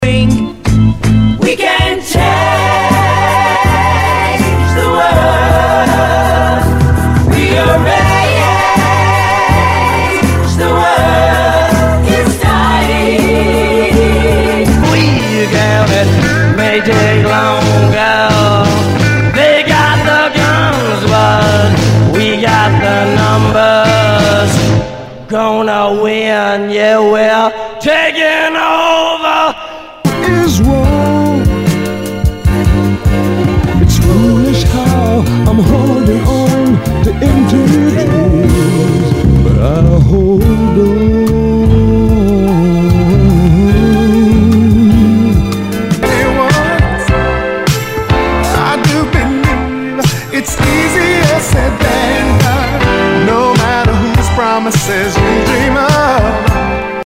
SOUL/FUNK/DISCO
全体にチリノイズが入ります